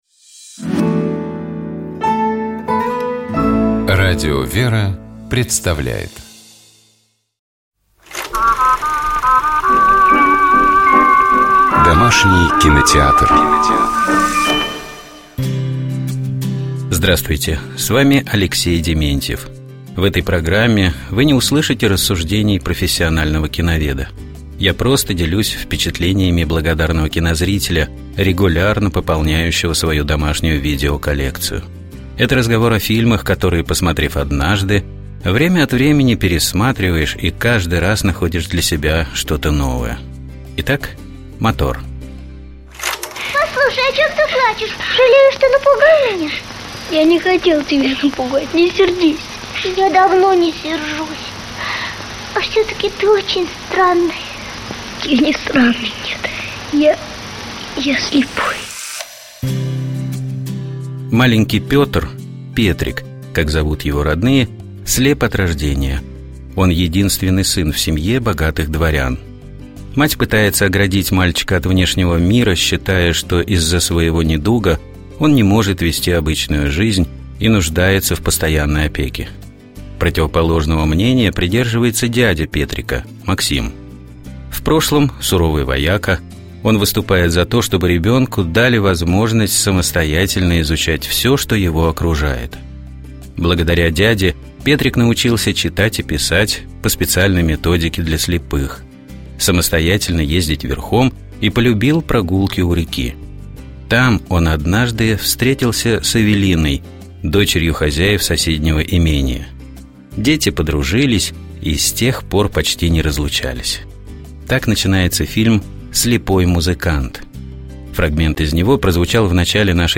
Фрагмент из него прозвучал в начале нашей программы.